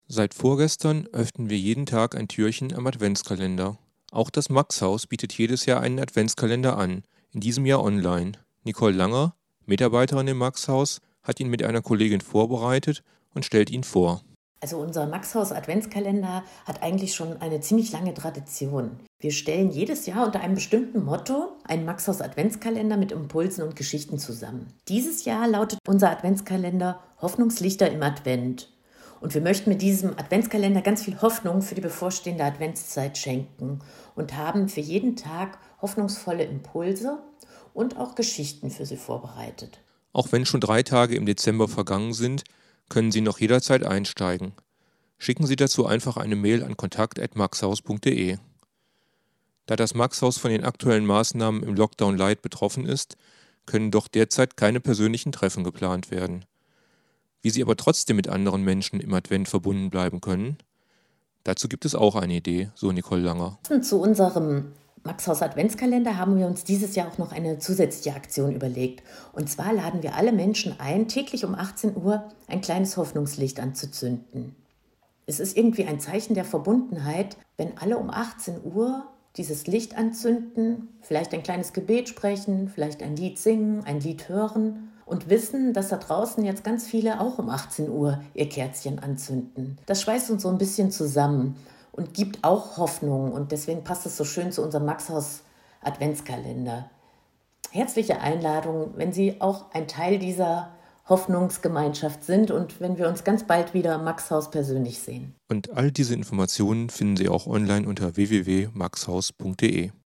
Antenne Düsseldorf: Interview